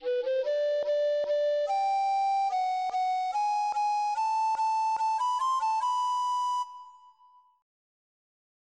The starling’s version is shown below and simulated in the audio clip underneath; click on the ‘Play’ arrow.